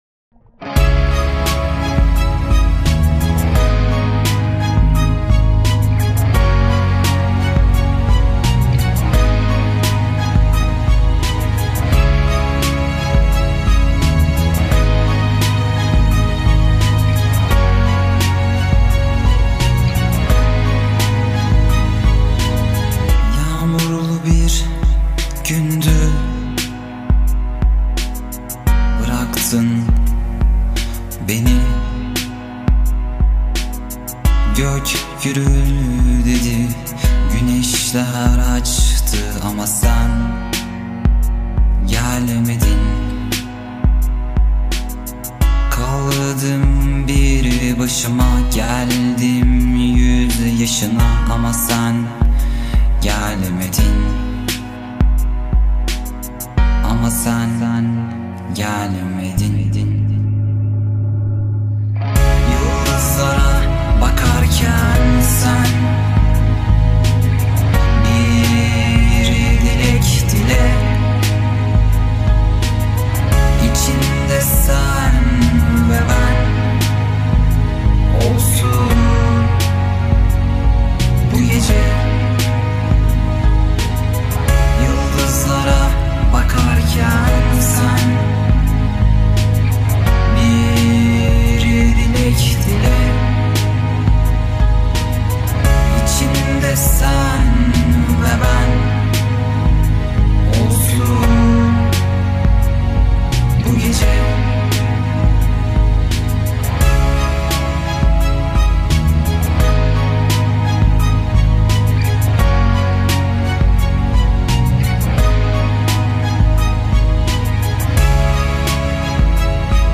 Турецкая музыка